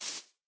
grass3.ogg